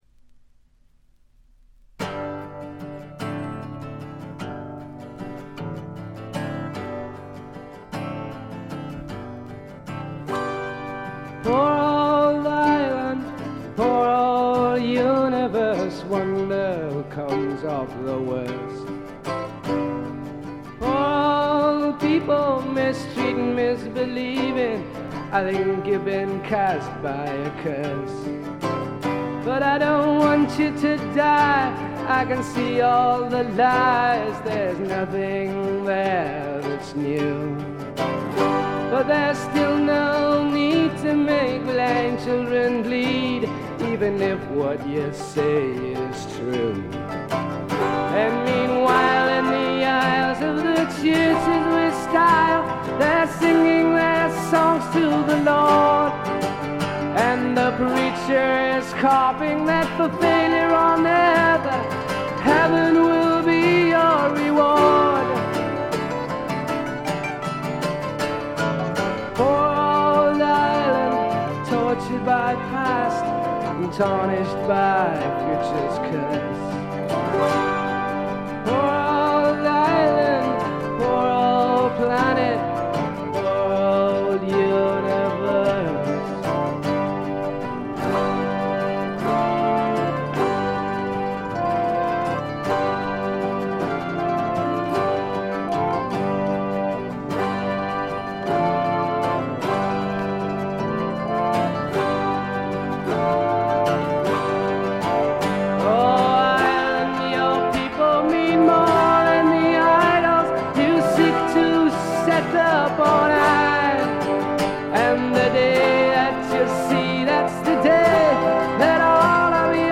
これぞ英国流フォークロックとも言うべき名作です。
試聴曲は現品からの取り込み音源です。